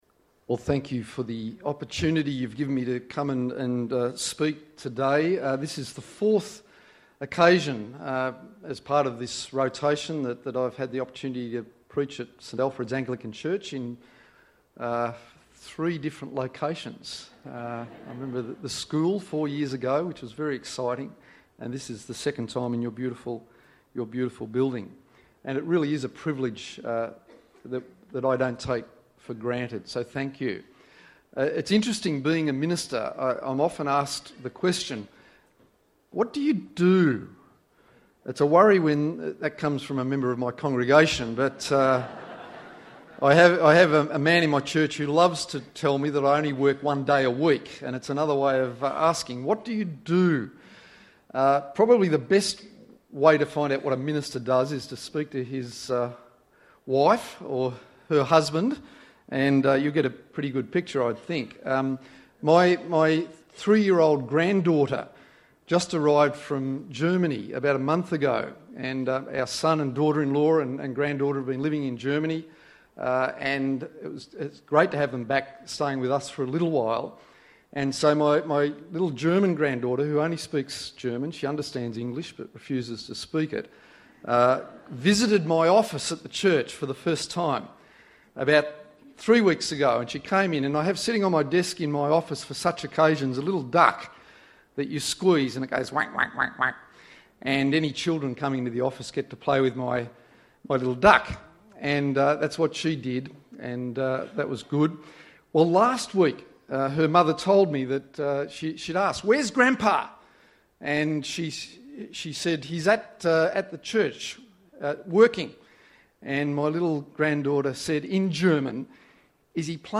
It is the third Sunday of Advent.